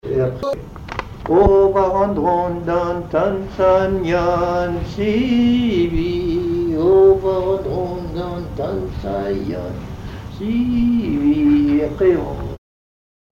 chant en breton
Pièce musicale inédite